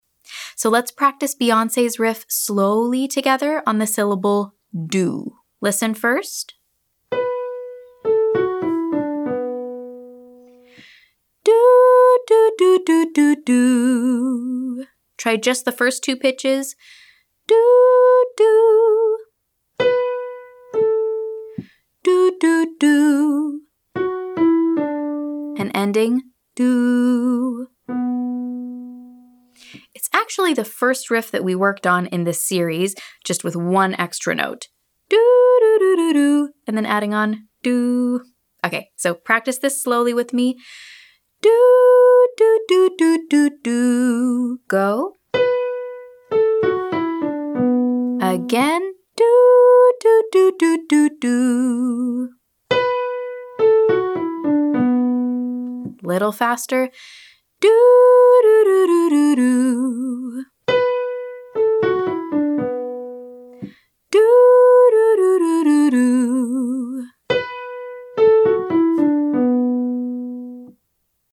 Improving Speed - Online Singing Lesson
Let’s try Beyoncé's riff slowly on the syllable DOO.